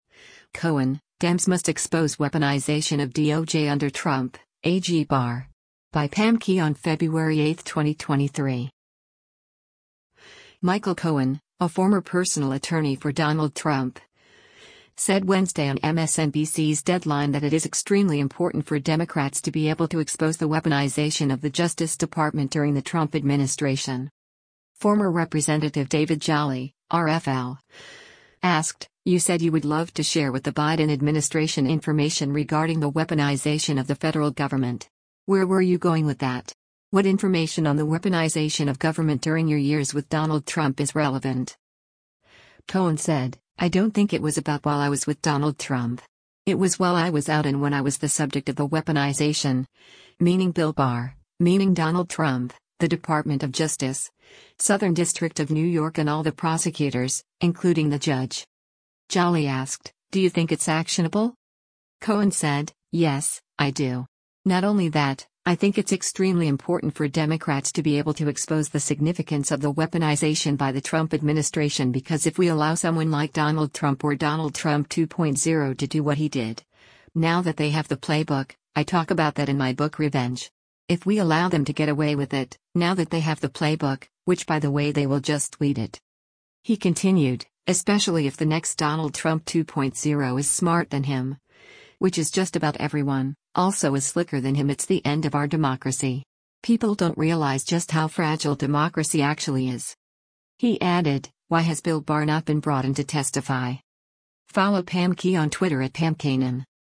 Michael Cohen, a former personal attorney for Donald Trump, said Wednesday on MSNBC’s “Deadline” that it is “extremely important for Democrats to be able to expose” the weaponization of the Justice Department during the Trump administration.